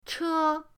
che1.mp3